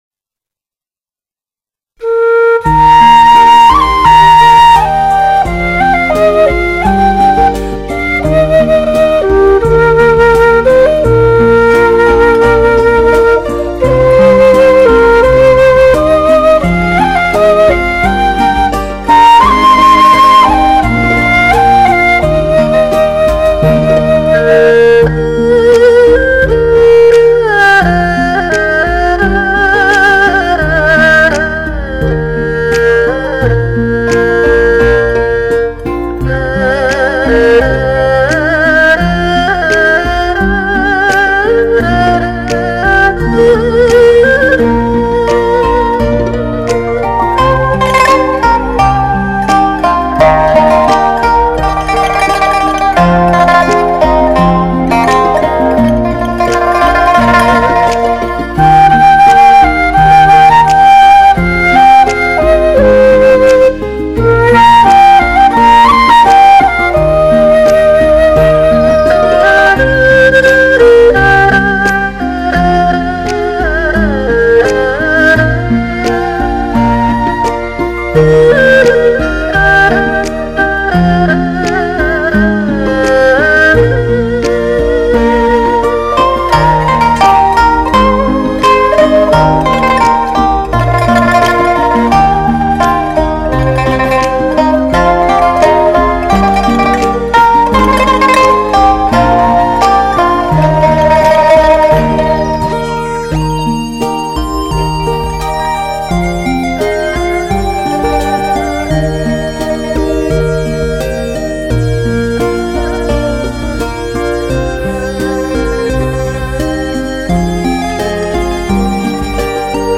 萧与琵琶